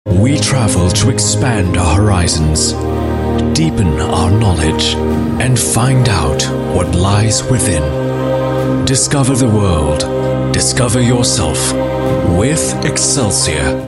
EN Asian
male